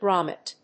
音節grom・met 発音記号・読み方
/grάmɪt(米国英語), grˈɔmɪt(英国英語)/